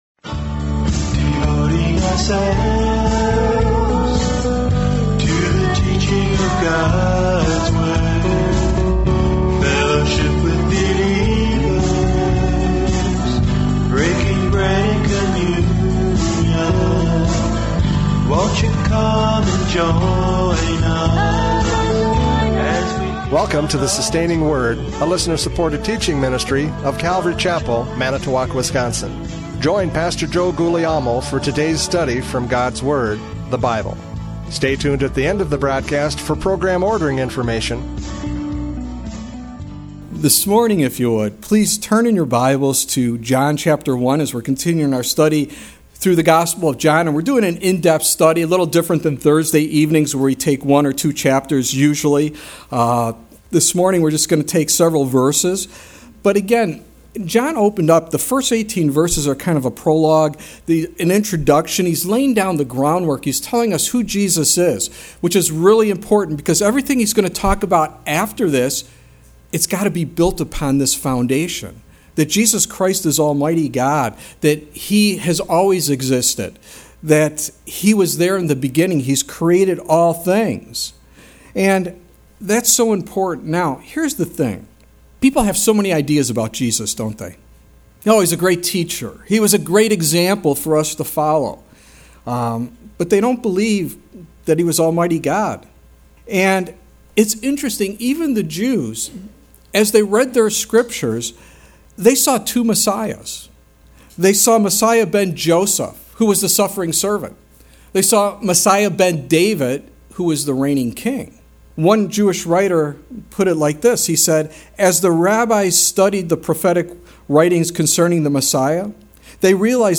John 1:19-28 Service Type: Radio Programs « John 1:14-18 The Incarnation!